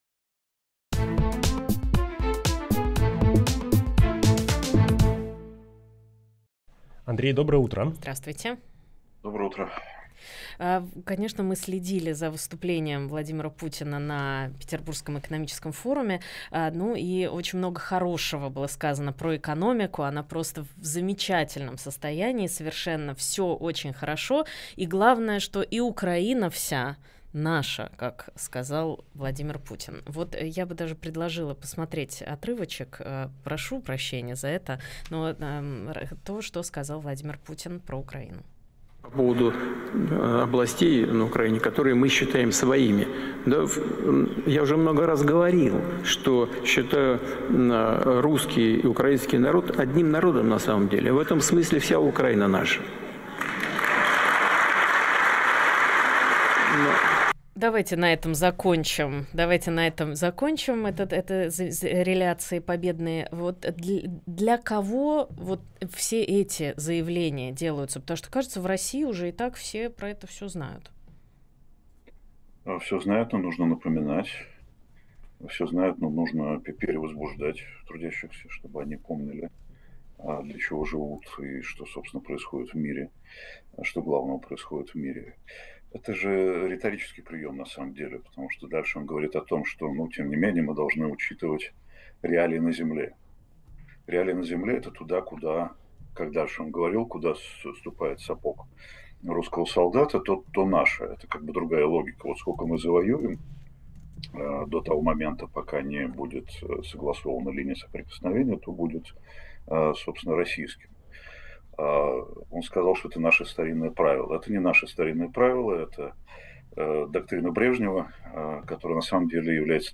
Фрагмент эфира от 21 июня.